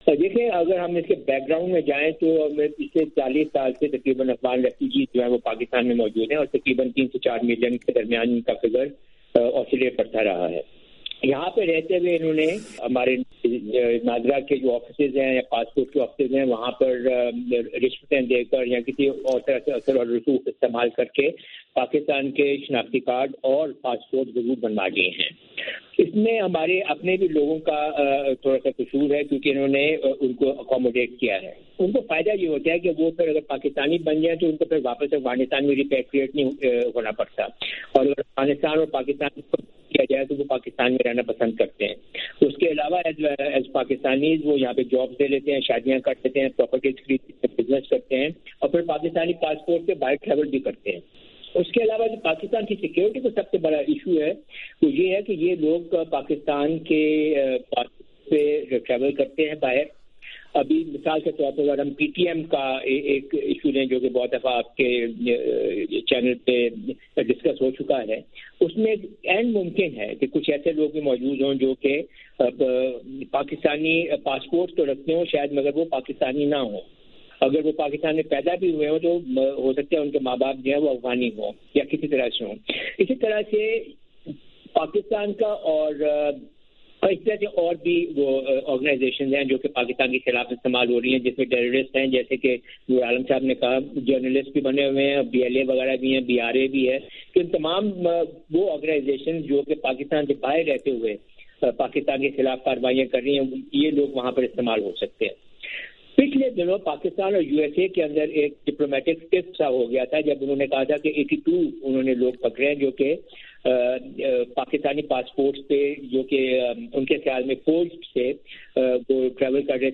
وائس آف امریکہ کے ریڈیو پروگرام میں اس بارے میں گفتگو کرتے ہوئے